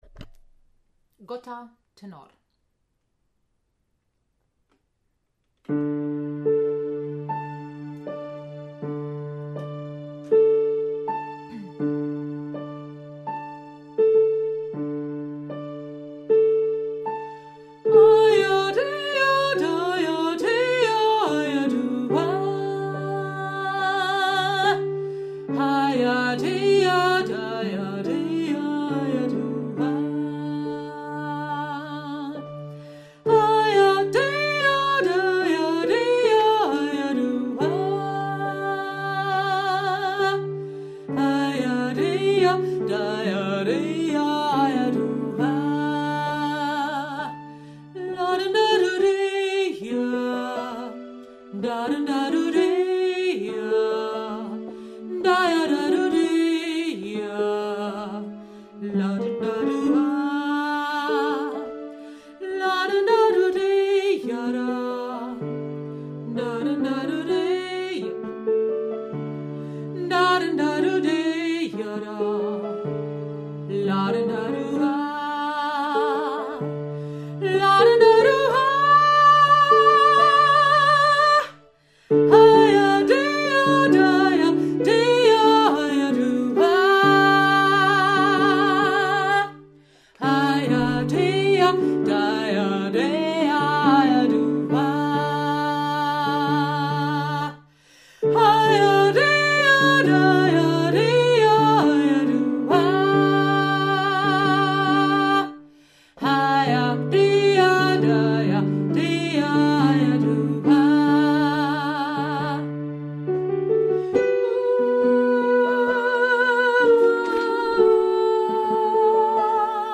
Gota – Tenor